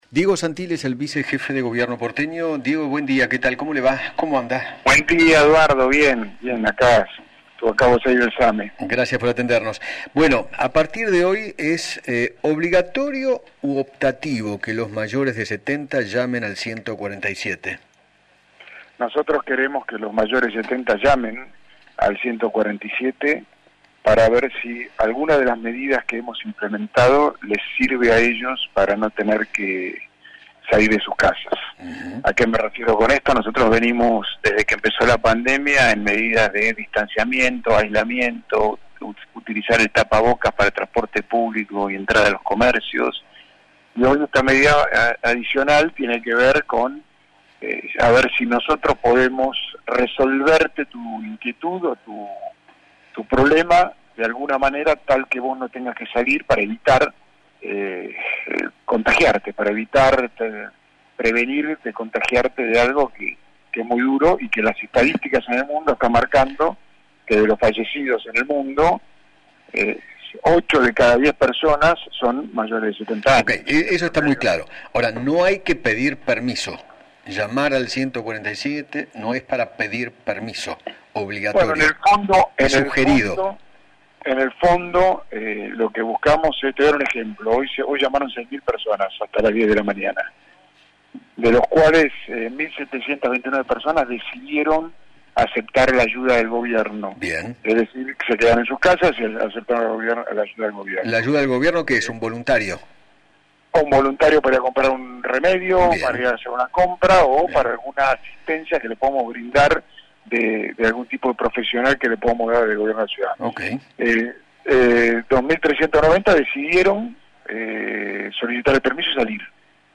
Diego Santilli, vicejefe de gobierno de la Ciudad de Buenos Aires, dialogó con Eduardo Feinmann sobre la resolución que puso en vigencia el Gobierno Porteño que obliga a los mayores de 70 años a comunicarse con el servicio de atención ciudadana antes de salir de sus casas.